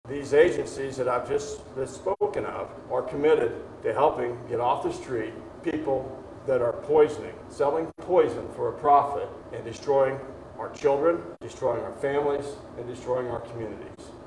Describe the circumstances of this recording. On Wednesday, leadership from RCPD, the Kansas Highway Patrol and Drug Enforcement Administration gathered at the Riley County Attorney’s Office building to announce four more arrests as part of their ongoing investigation.